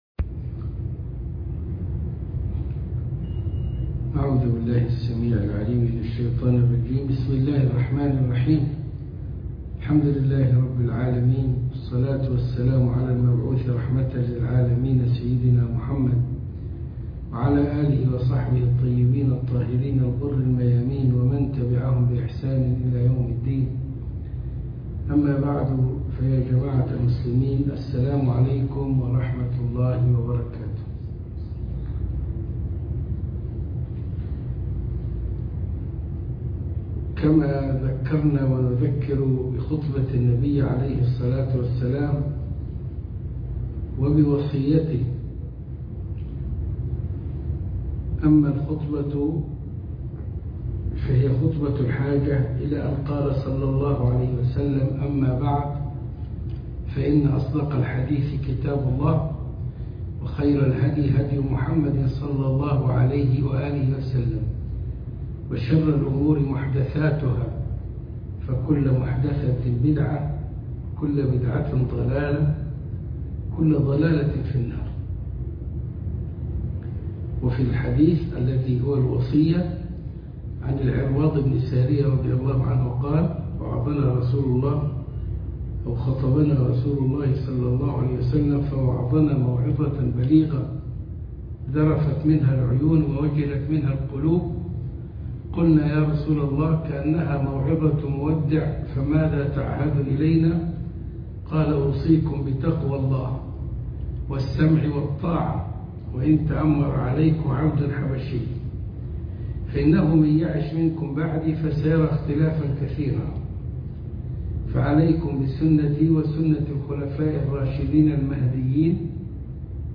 خاطرة المغرب من مسجد الروضة من مونتريالكندا3